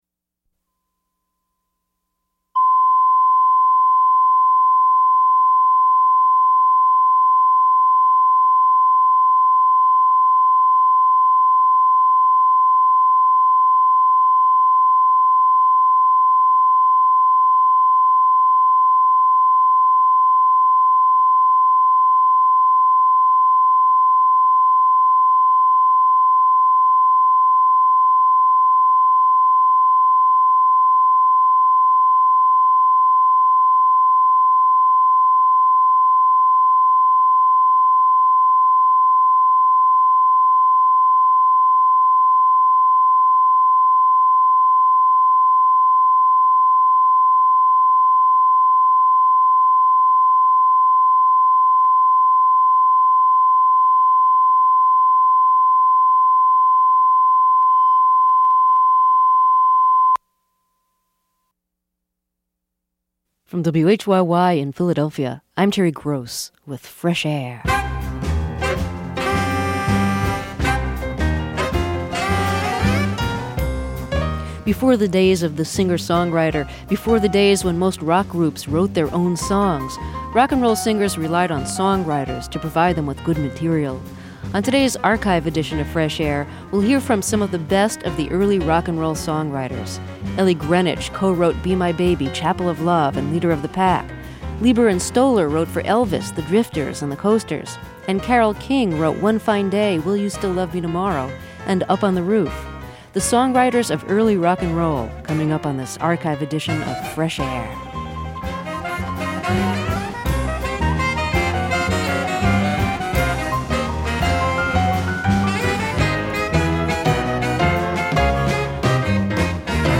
She left the music business for several years, but the late Eighties, recorded a new album. Here she talks about her hit song, "Be My Baby".